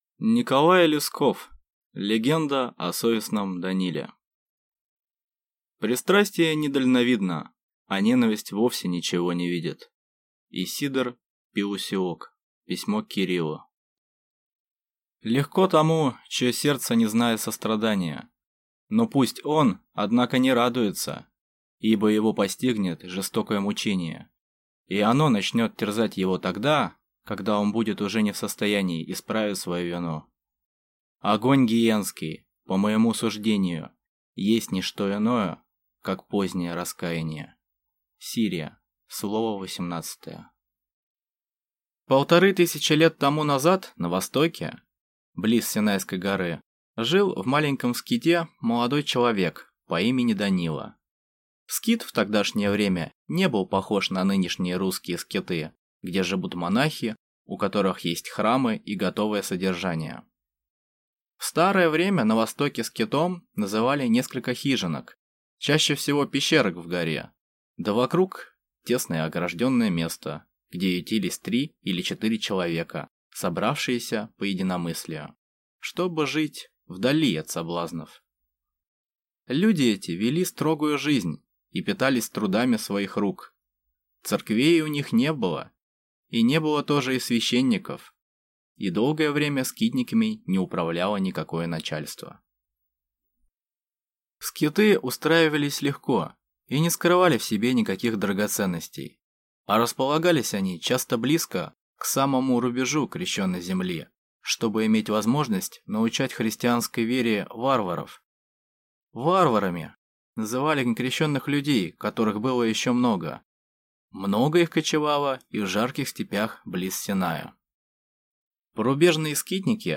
Аудиокнига Легенды о совестном Даниле | Библиотека аудиокниг